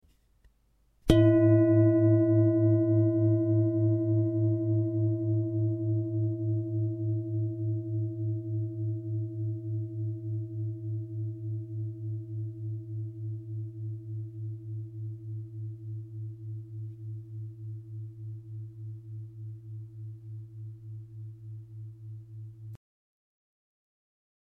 Indische Bengalen Klangschale - BECKENSCHALE
Durchmesser: 27,4 cm
Grundton: 107,82 Hz
1. Oberton: 333,79 Hz